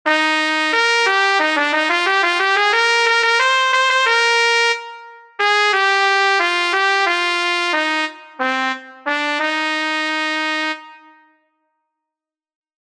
Exercise 2: 2/4 time signature.
Melodic reading practice exercise 2
melodic_reading_2.mp3